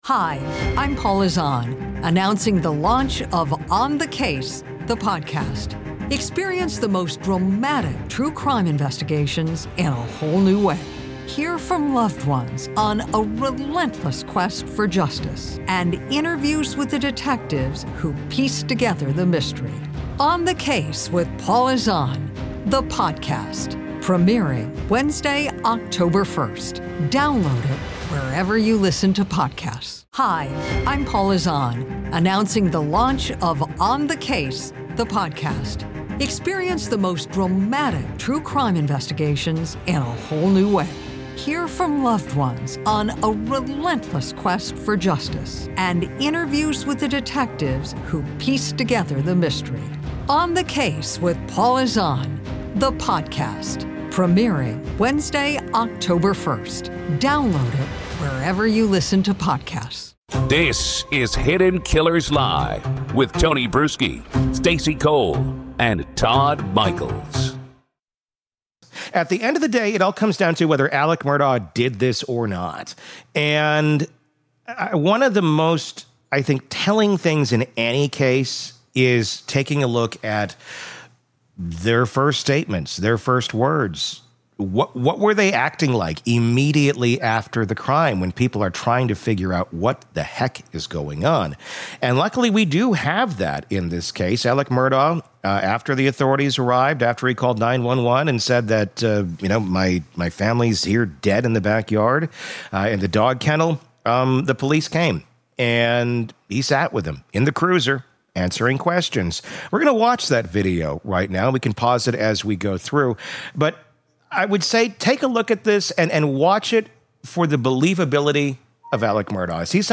In this gripping segment, we analyze one of the most critical pieces of evidence in the Alex Murdaugh case: his first full interrogation with police, recorded from the back of a cruiser at the chaotic crime scene.
We play the raw footage, allowing you to see and hear for yourself how Murdaugh describes the horrific discovery.